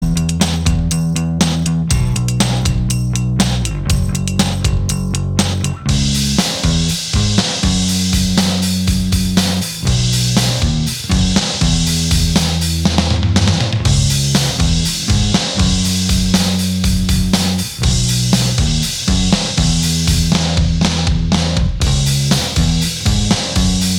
Minus All Guitars Rock 3:00 Buy £1.50